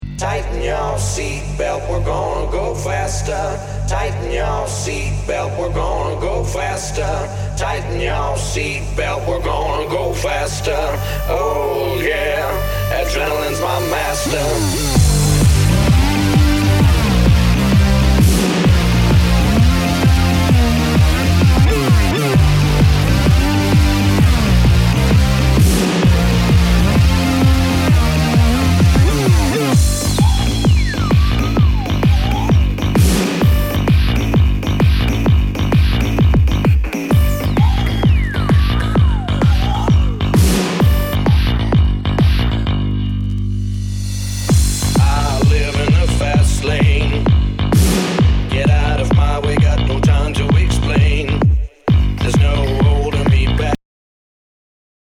HOUSE/TECHNO/ELECTRO
ナイス！ユーロ・ヴォーカル・ハウス！